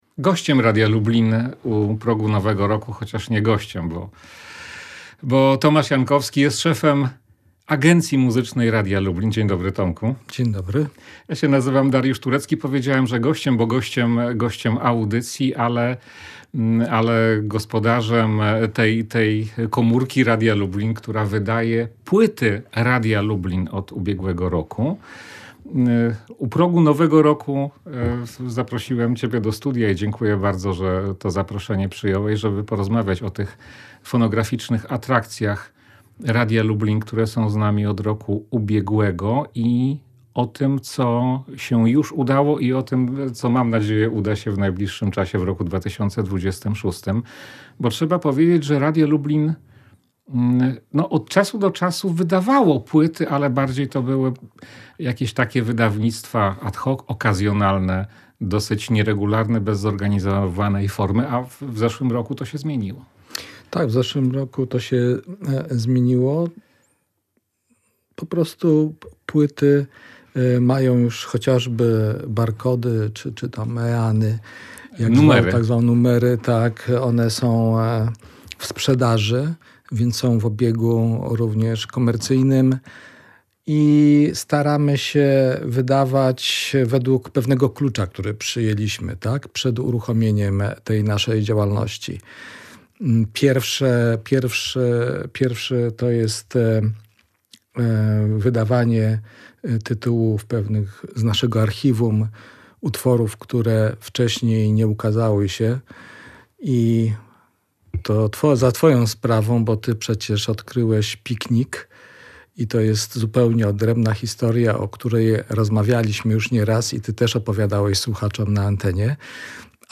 Rozmawiają w Radiu Lublin 1 stycznia 2026 roku między godziną 16:00 a 18:00.